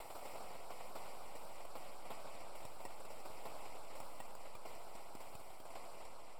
Rain3.wav